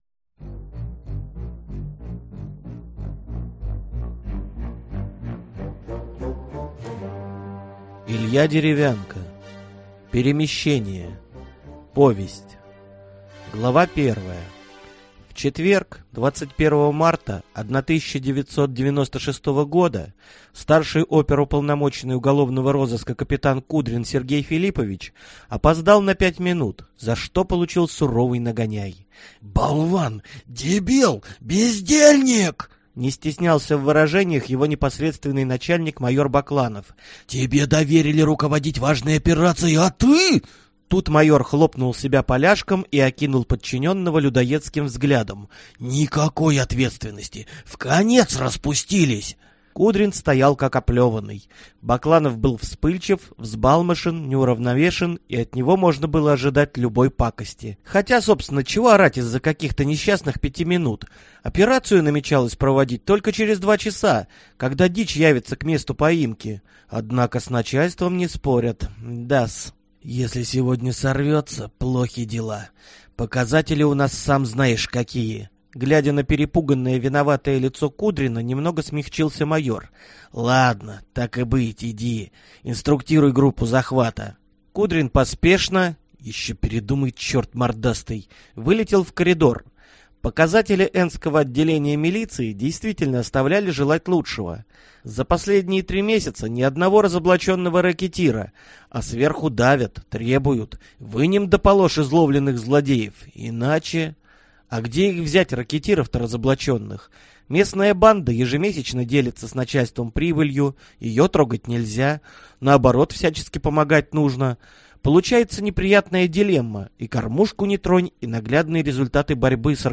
Скачать, слушать онлайн аудиокнигу Перемещение